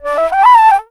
59 FLUTE 1-R.wav